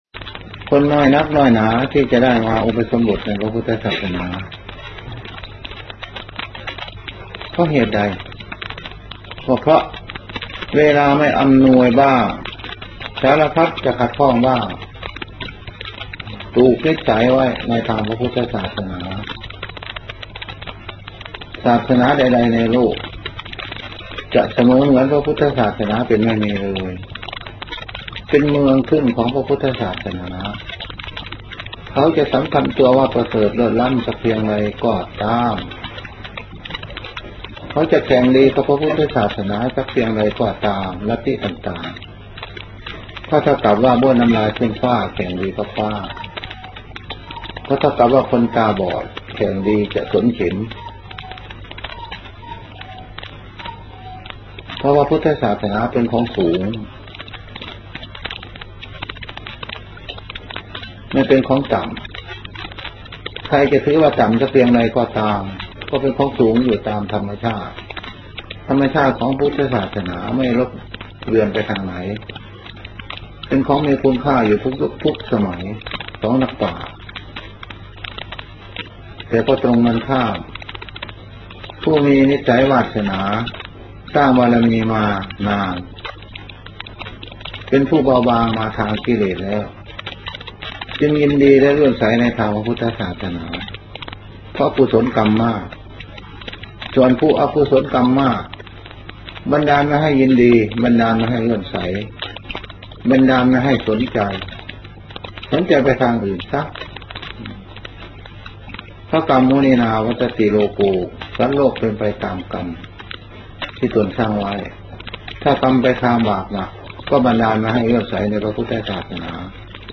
ศาสตราจารย์ ระพี สาคริก บรรยายใจหัวข้อ คุณธรรมและจริยธรรมในการบริหารและจัดการ การประชุมสัมมนาเรื่อง การบริหารงานส่งเสริมการเกษตร สำหรับหัวหน้างานระดับอำเภอ จัดโดย สำนักงานส่งเสริมการเกษตรภาคกลาง